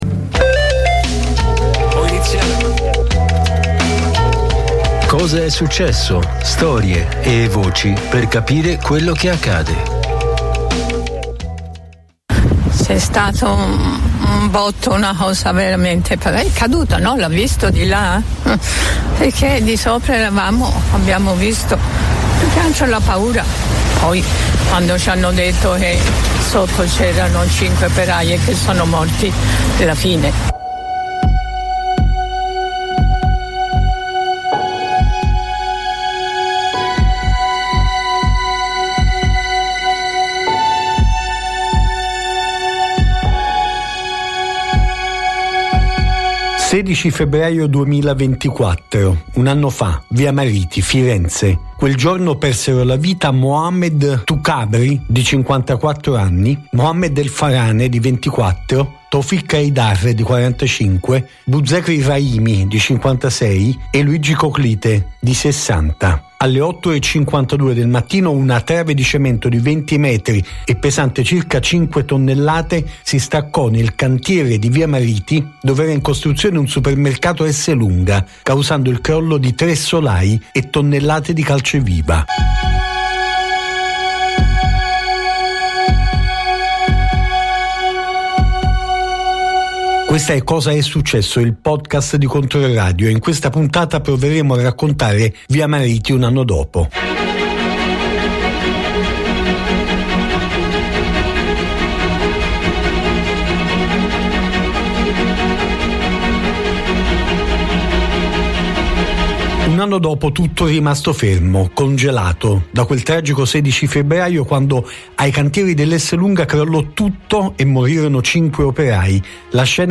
Basta morti sul lavoro! Facciamoci un parco! E poi i nomi dei 5 operai morti sotto il crollo del cantiere Esselunga di via Mariti un anno fa; queste le parole scandite nel corso del presidio che nel pomeriggio di domenica 16 febbraio 2025 ha animato il quartiere. Si sono alternati interventi di sindacati di base e voci di varie parti del movimento fiorentino, ma anche la testimonianza del Comitato 29 giugno di Viareggio, che dal 2009 si batte per verità e giustizia per le 32 vittime del disastro ferroviario.